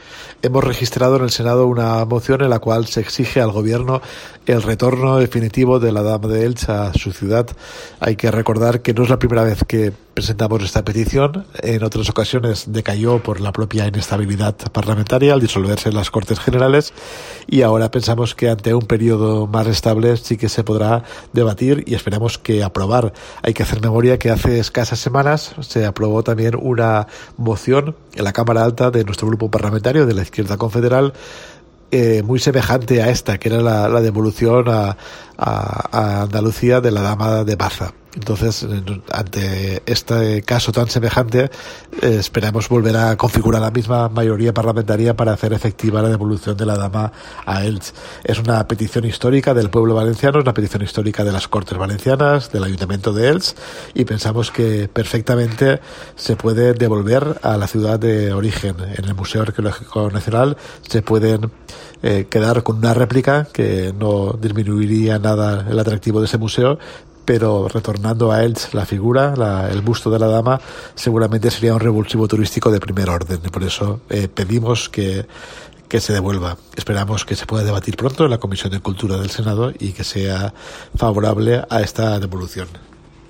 Declaraciones de Carles Mulet: